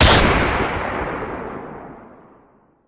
gunshot.wav